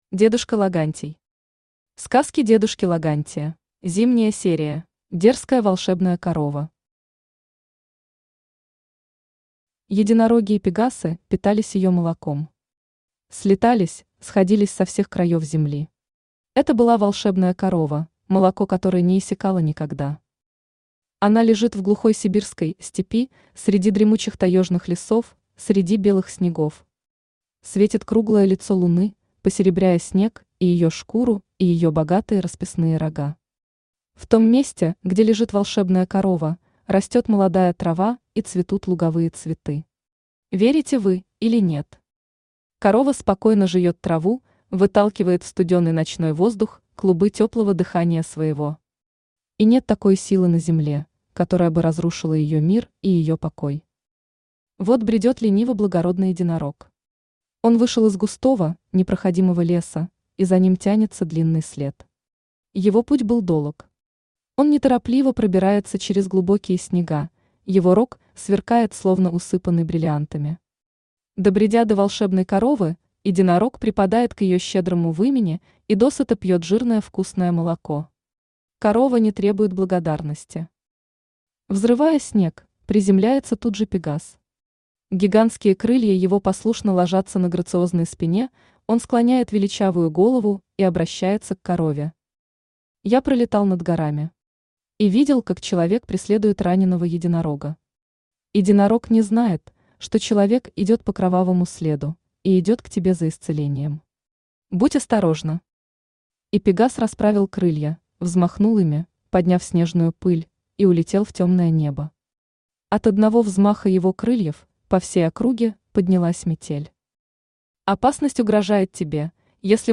Аудиокнига Сказки дедушки Логантия | Библиотека аудиокниг
Aудиокнига Сказки дедушки Логантия Автор дедушка Логантий Читает аудиокнигу Авточтец ЛитРес.